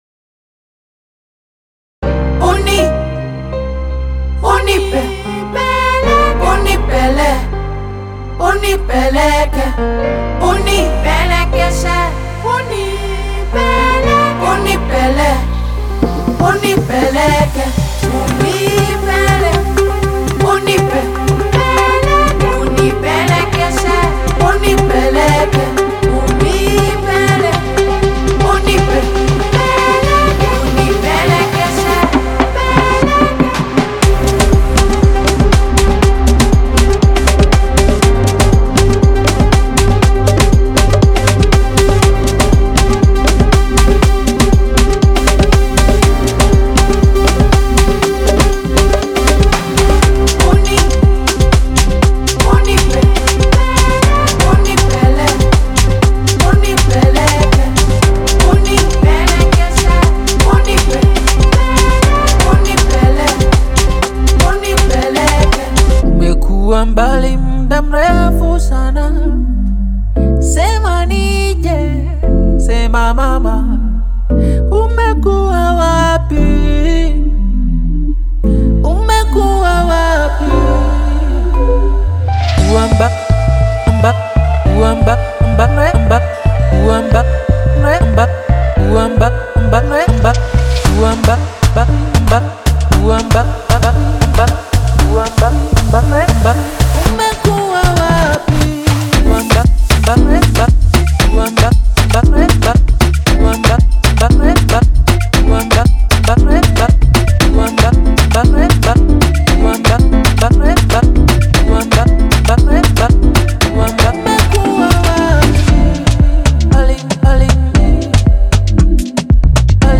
アフロハウス系では特に使い勝手も良く、 重宝するサンプルパックです。
ご注意：オーディオデモは音量を上げ、圧縮し、均一化した処理が施されています。